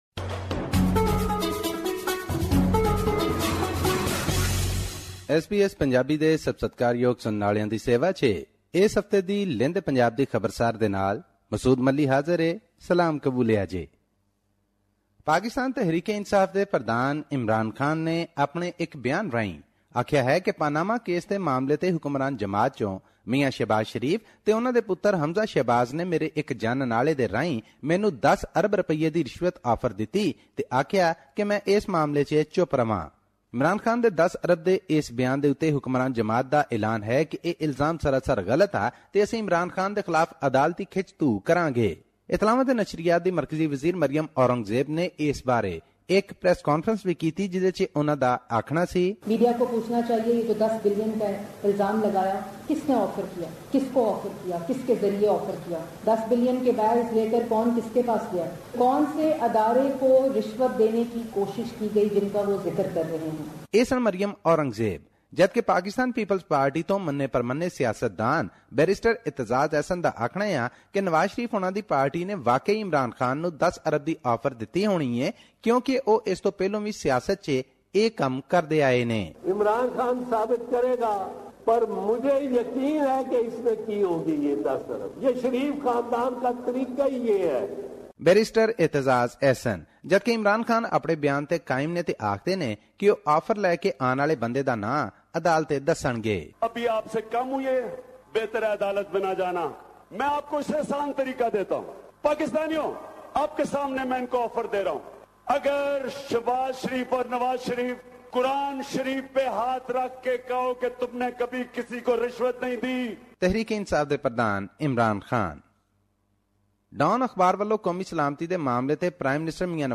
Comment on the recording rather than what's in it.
His report was presented on SBS Punjabi program on Tuesday, May 02 2017, which touched upon issues of Punjabi and national significance in Pakistan. Here's the podcast in case you missed hearing it on the radio.